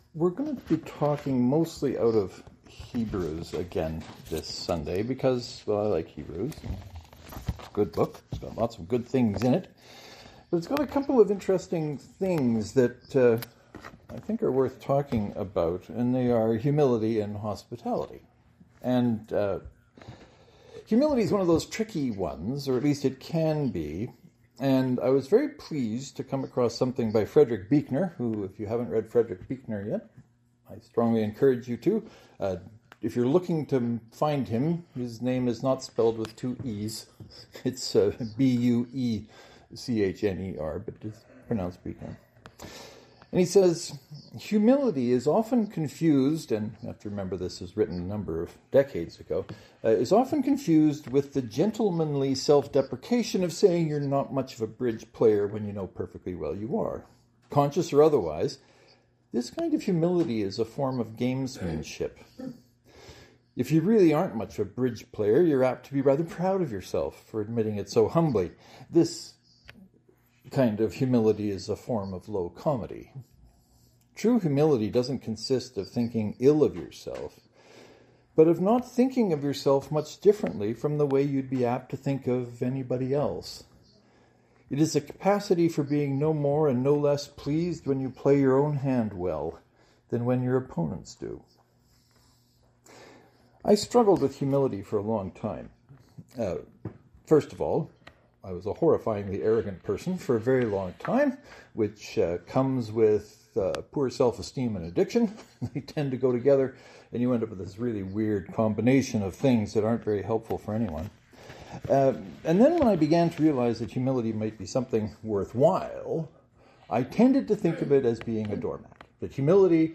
This Sunday, I felt it was time to talk about humility, hospitality and how they relate to love.